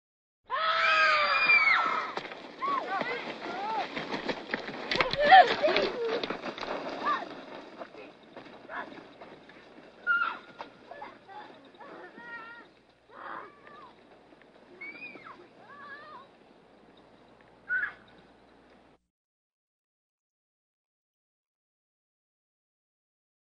Звук играющих на улице детей
Категория: Звуки людей
Дети кричат, смеются и отбегают на общий план — 23 сек